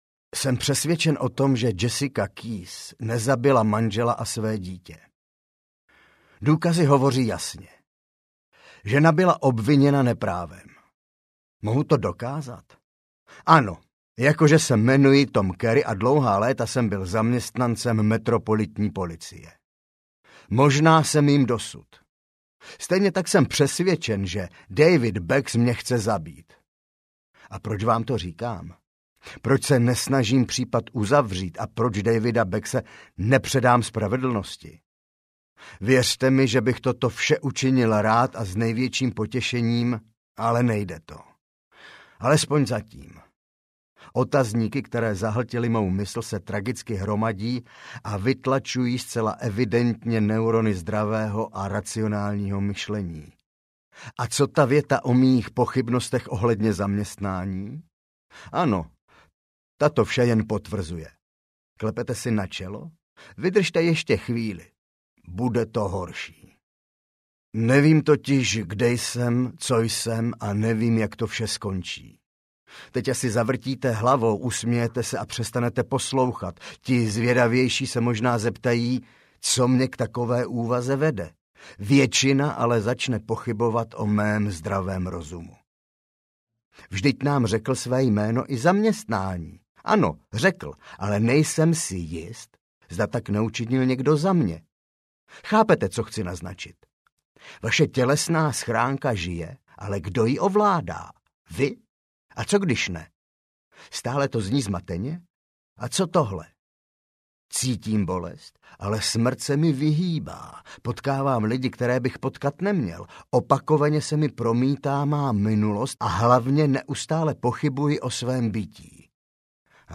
Ukázka z knihy
verte-mi-audiokniha